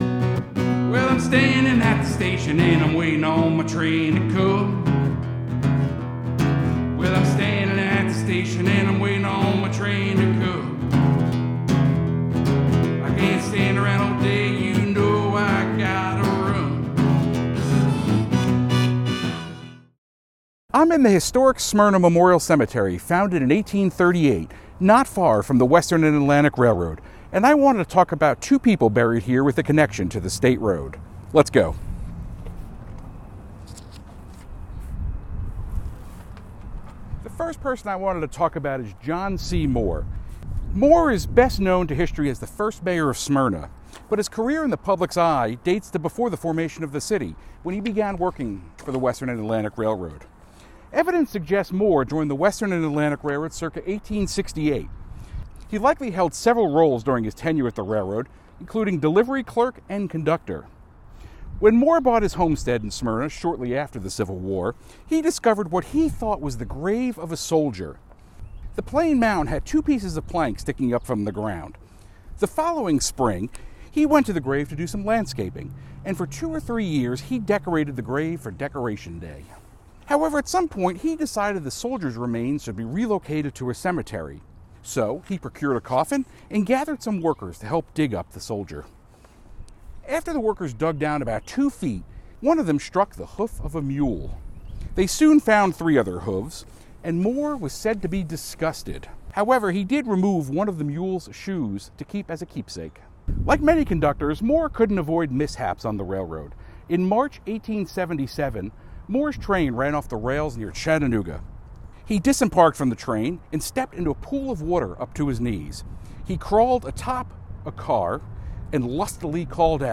For this first episode of “People and Places of the Western & Atlantic Railroad,” we’re in the historic Smyrna Memorial Cemetery in Smyrna, Georgia, not far from the Western & Atlantic Railroad. Today, we focus on two people buried here with a connection to the State Road.